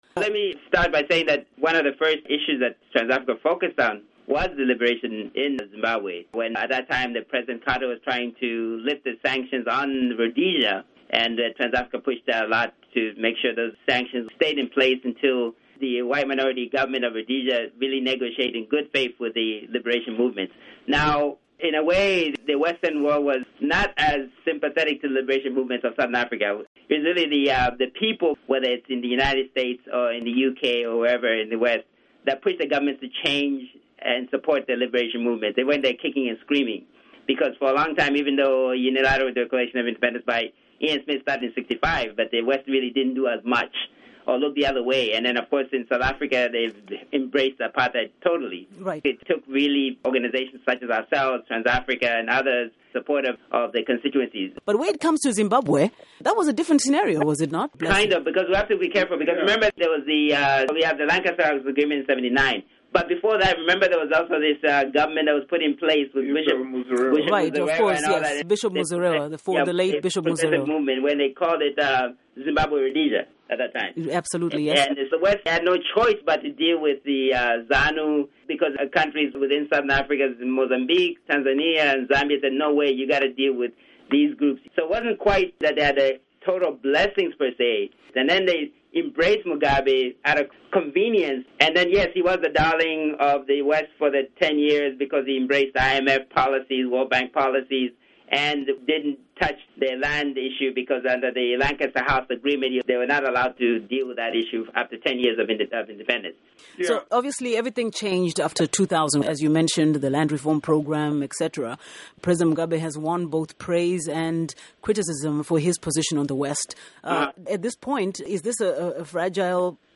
International Perspective on Zanu PF - Interview